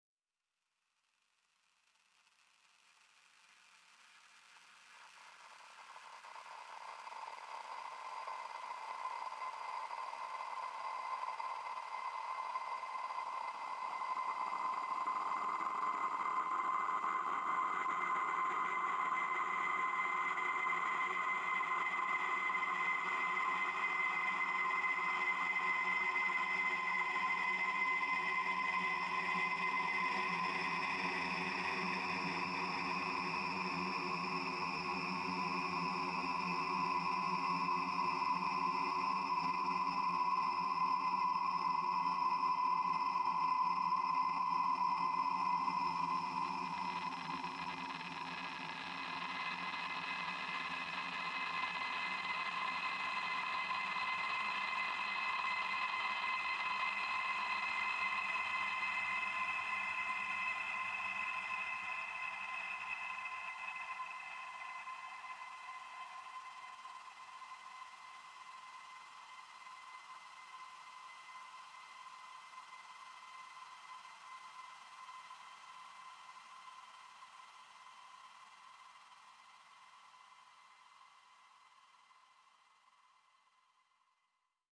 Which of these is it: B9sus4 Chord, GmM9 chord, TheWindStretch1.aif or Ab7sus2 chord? TheWindStretch1.aif